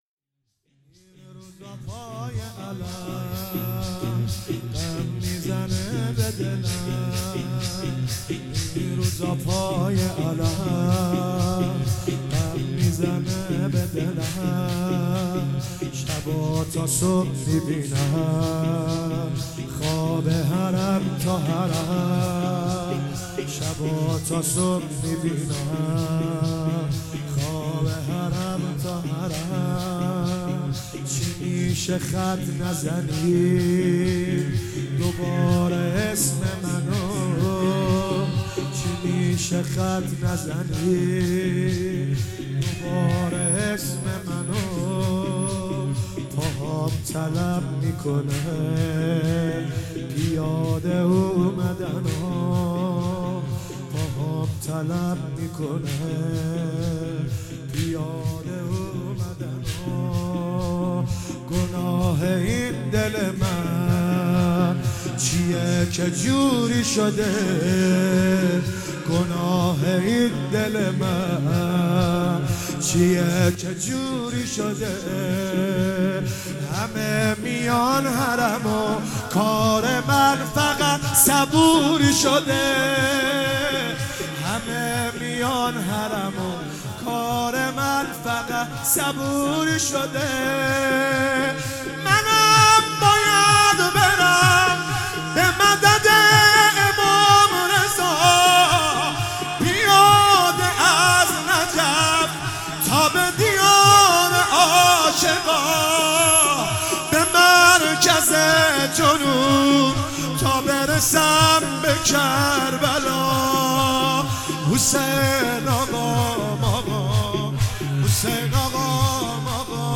شور
مداحی